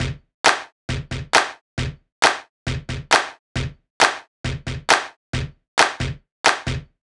Jfx Bd _ Snr 2.wav